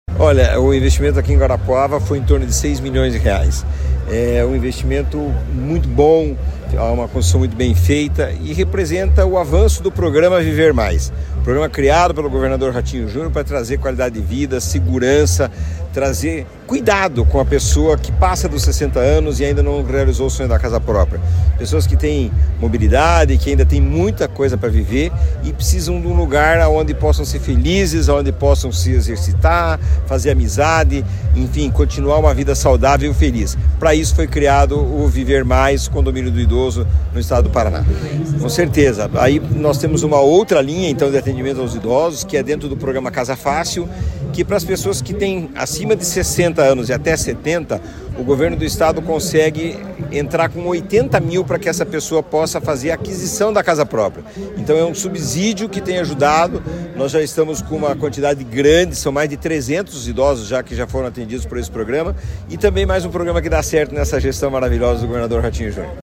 Sonora do presidente da Cohapar, Jorge Lange, sobre o Condomínio do Idoso de Guarapuava